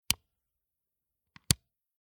"Walkman WM-DD II" cassette player
Play button, rewind button, stop button
play-button-rewind-button-stop-button.mp3